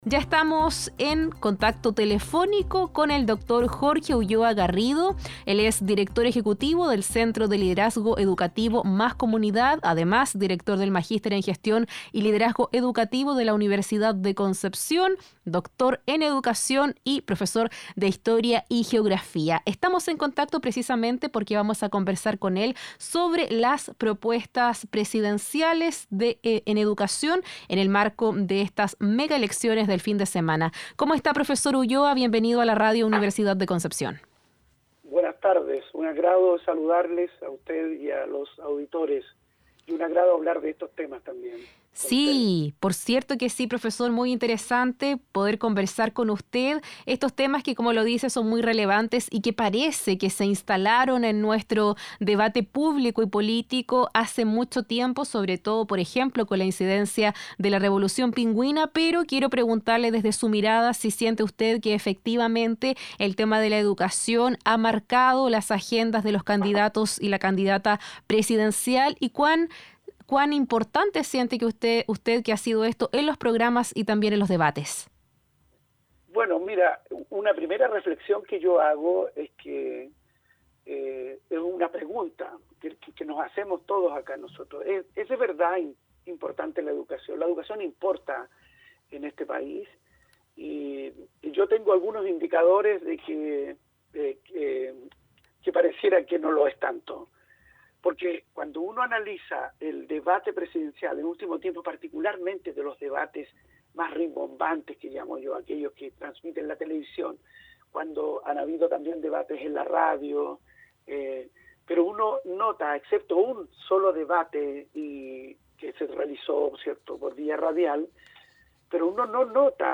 entrevista-13-hrs-19-nov.mp3